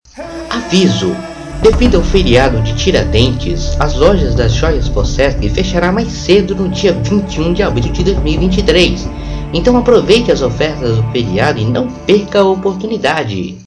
ÁUDIOS DE AVISO